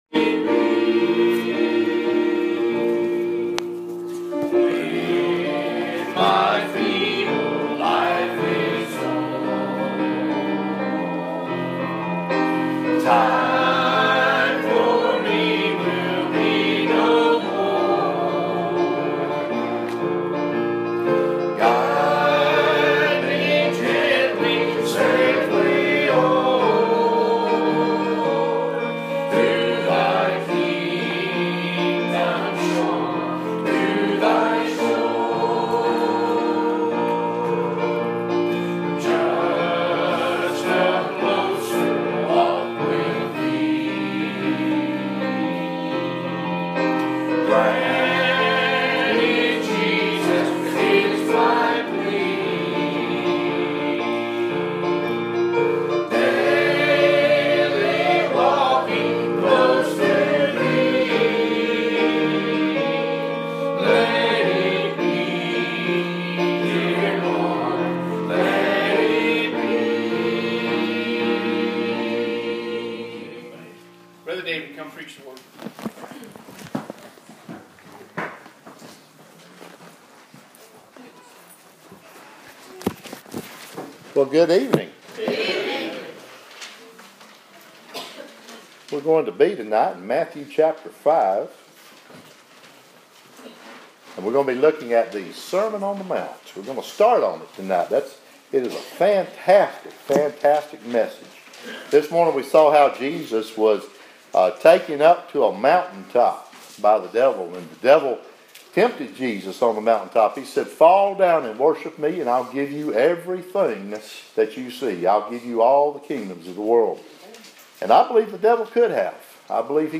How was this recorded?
Preached on the Evening January 29 2017 at Riverview 798 Santa Fe Pike Columbia TN